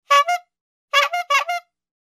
Звуки клоунов
Гудок клоунского велосипеда